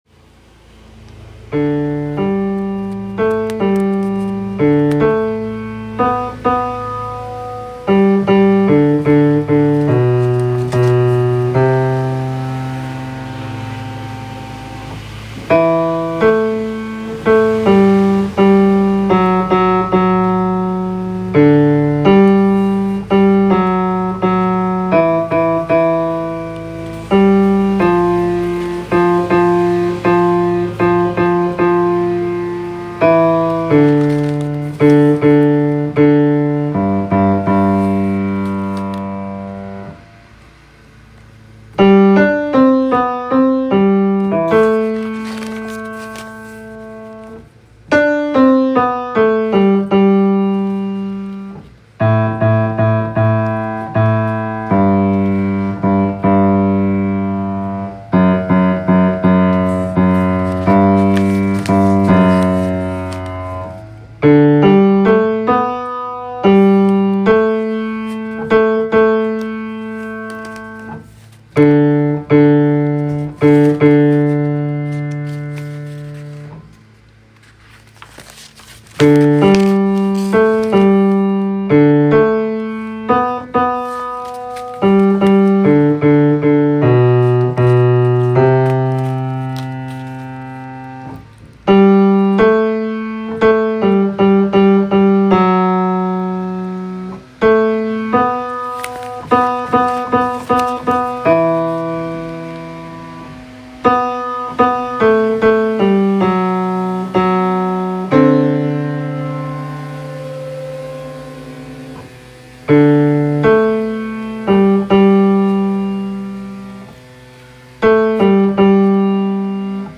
Come Live With Me and Be My Love Bass.m4a